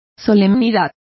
Complete with pronunciation of the translation of solemnity.